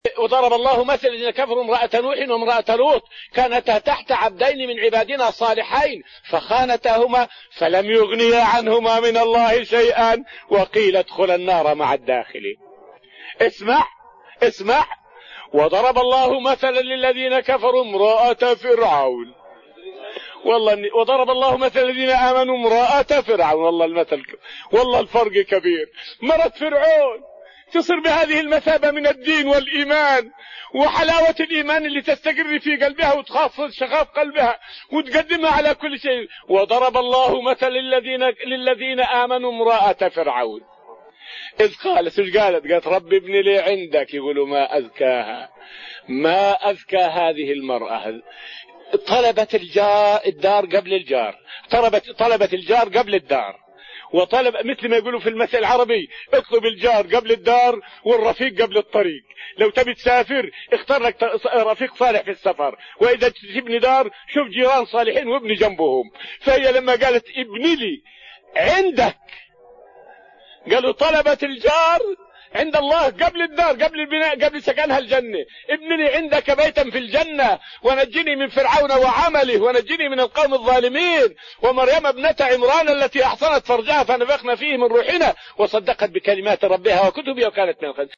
فائدة من الدرس السادس من دروس تفسير سورة الأنفال والتي ألقيت في رحاب المسجد النبوي حول إسلام عكرمة بن أبي جهل رضي الله عنه.